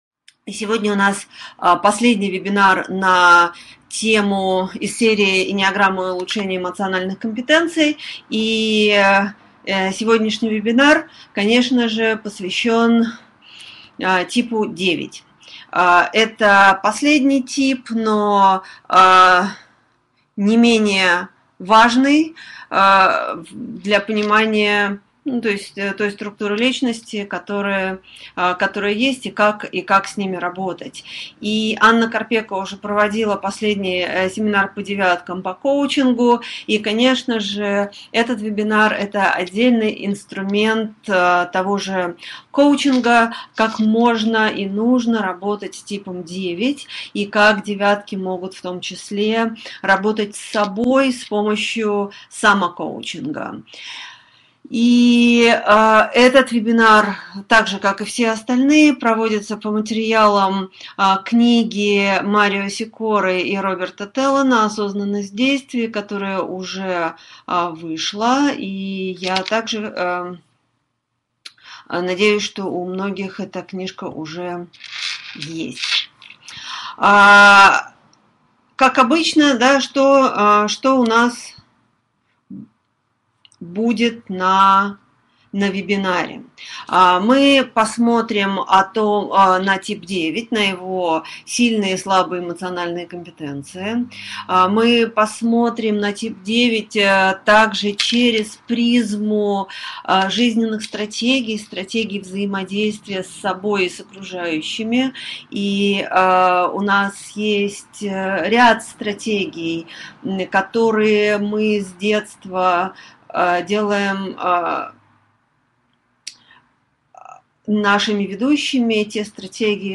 Аудиокнига Осознанность в действии. Тип 9 | Библиотека аудиокниг